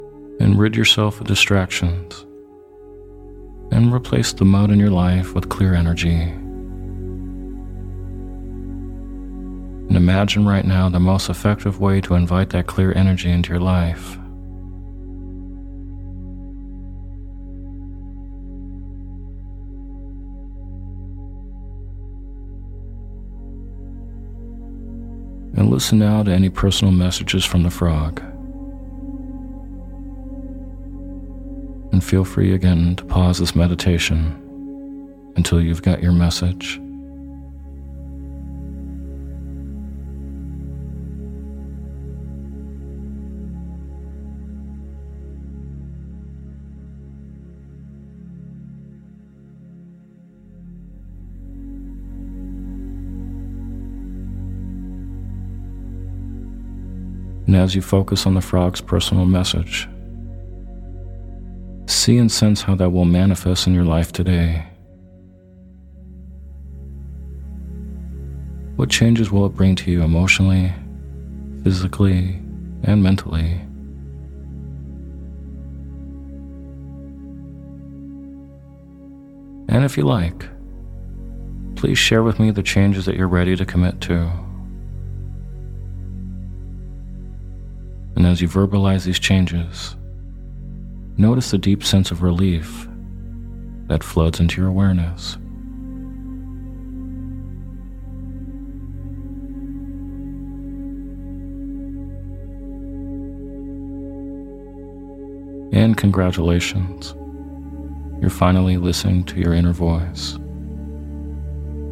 In this brief 12 min meditation, I’ll be guiding to visualize yourself connecting to your intuition or inner voice.